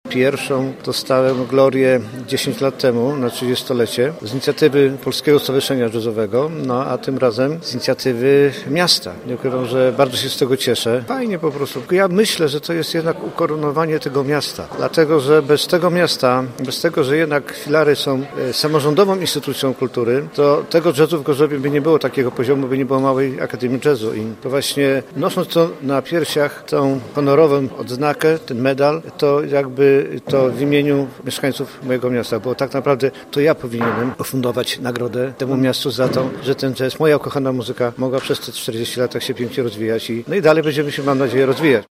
Tegoroczna inauguracja sezonu artystycznego, która odbyła się w gorzowskiej Filharmonii była również okazją do wręczenia medali i odznaczeń Ministra Kultury i Dziedzictwa Narodowego.
Jak twierdzi gość honorowy uroczystości prezydent miasta Jacek Wójcicki, w tym roku po raz kolejny uhonorowane zostały wyjątkowe osobowości.